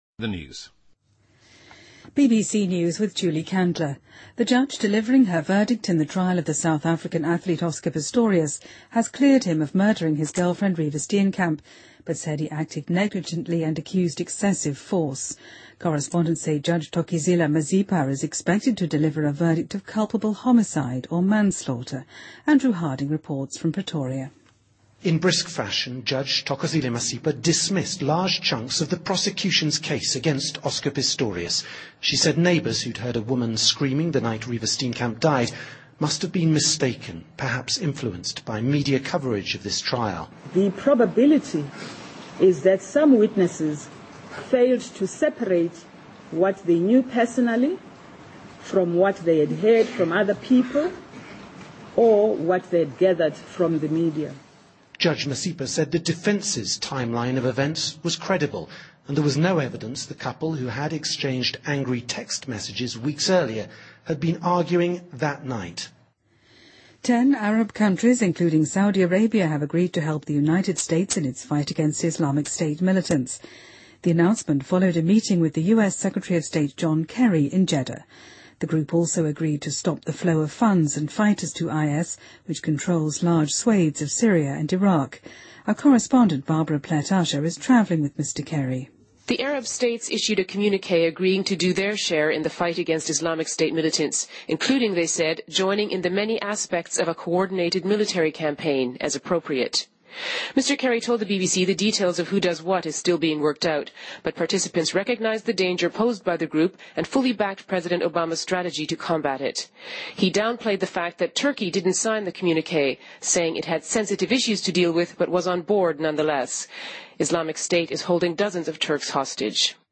Date:2014-09-10Source:BBC Editor:BBC News